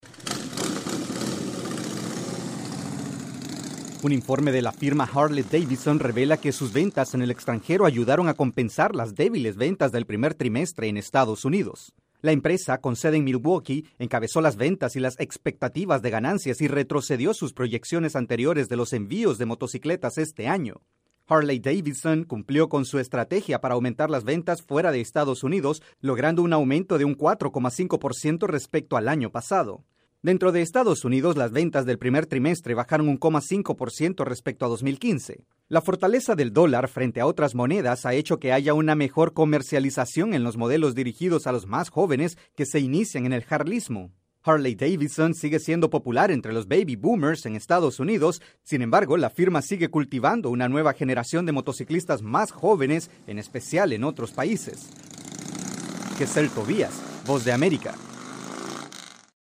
Informe de economía. La empresa de motocicletas Harley-Davidson repunta sus ventas a nivel internacional.